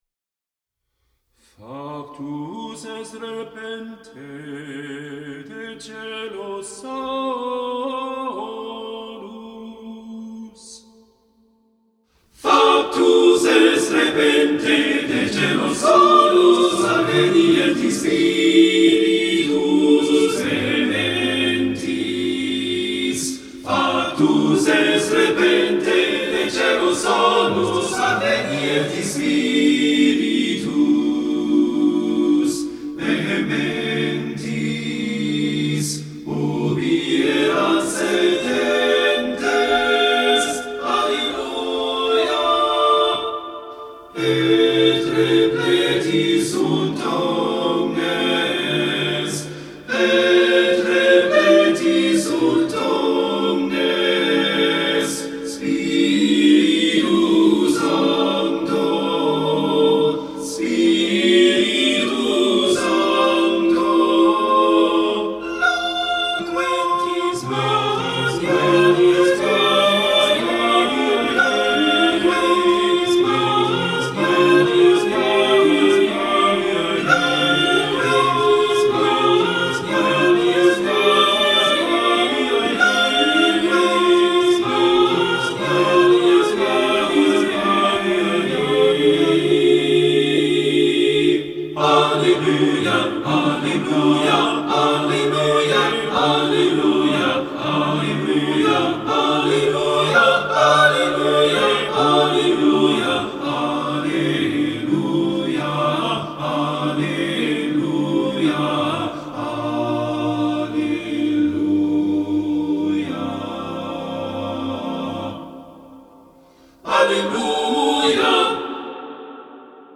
This piece is part of an Easter Motet series that will contain settings of all the communion propers in the liturgical season of Easter.